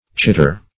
Chitter \Chit"ter\, v. i. [Cf. Chatter.]